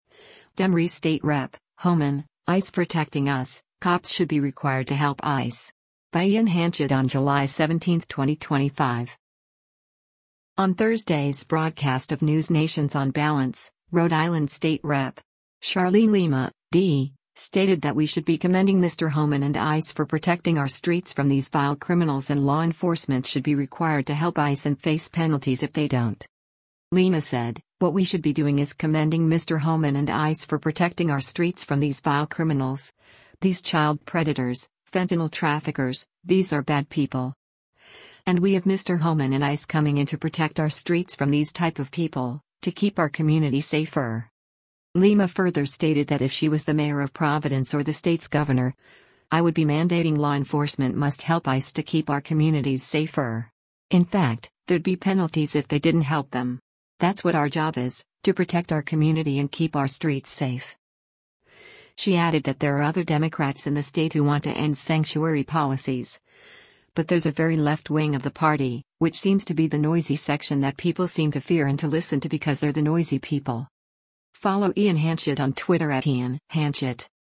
On Thursday’s broadcast of NewsNation’s “On Balance,” Rhode Island State Rep. Charlene Lima (D) stated that we should be “commending Mr. Homan and ICE for protecting our streets from these vile criminals” and law enforcement should be required to help ICE and face penalties if they don’t.